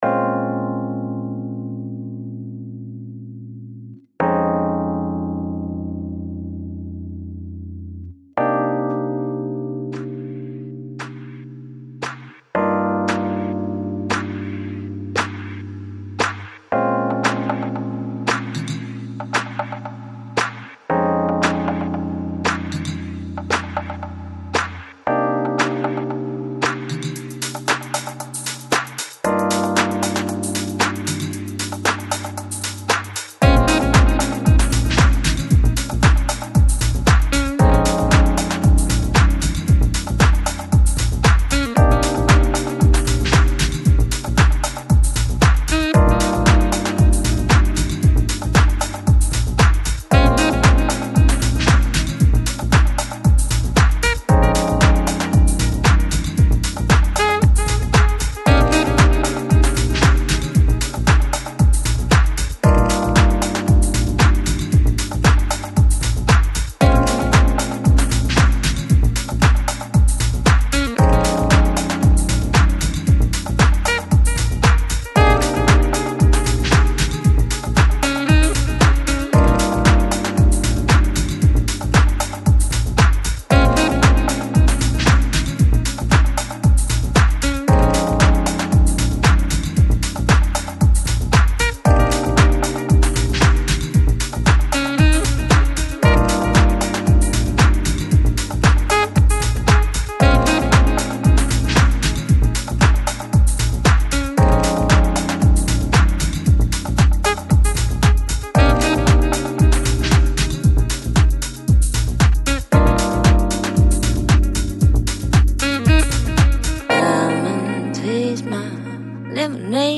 Electronic, Downtempo, Chill Out, Lounge